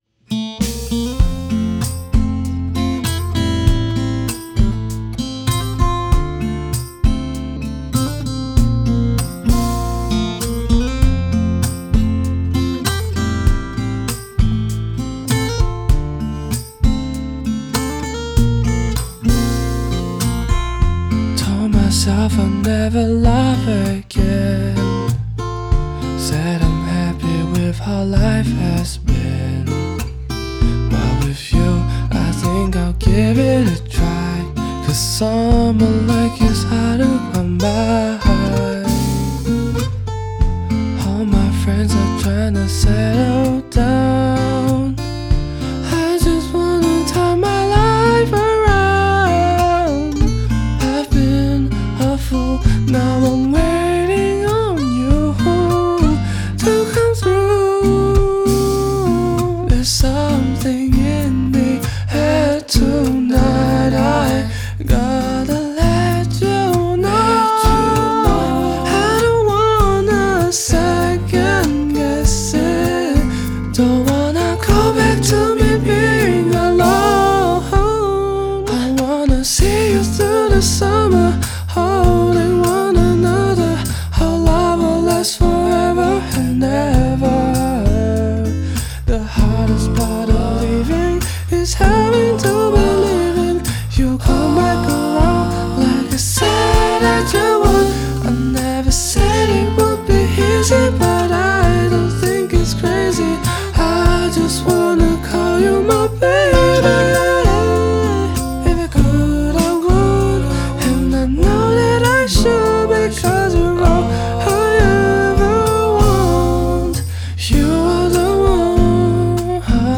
ballades folk/pop